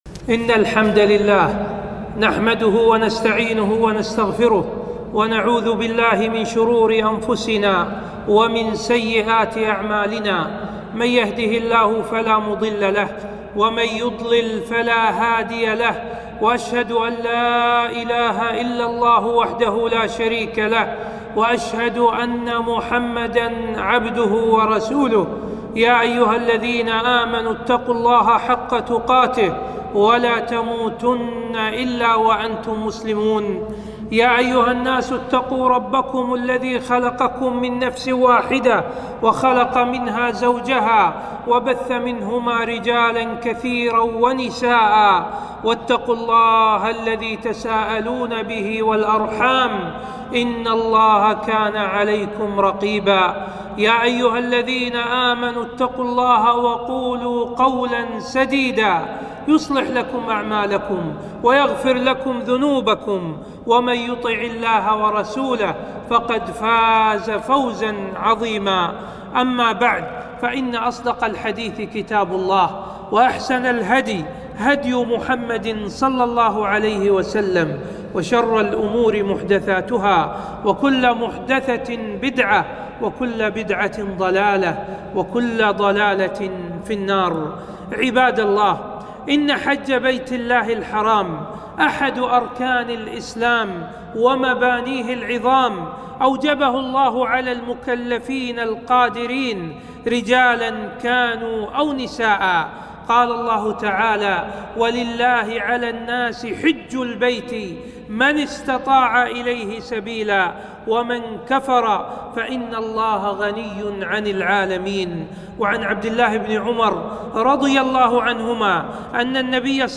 خطبة - وجوب الحج وشروطه